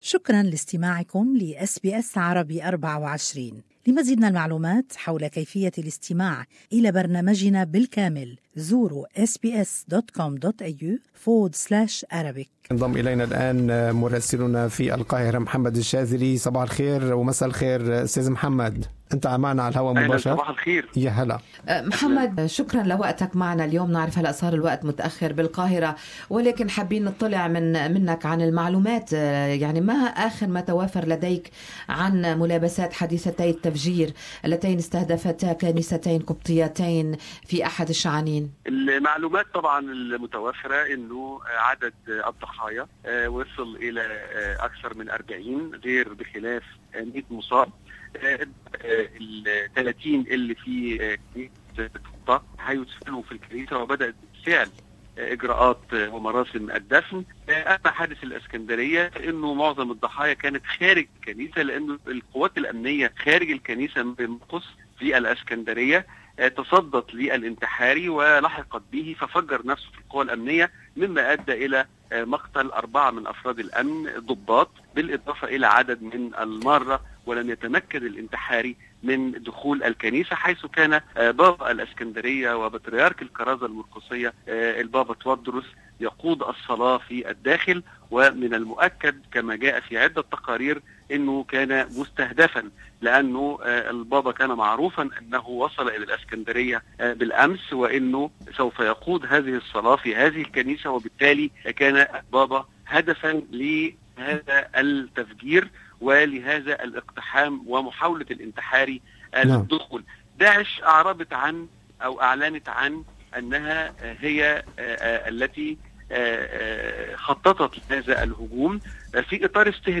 live interview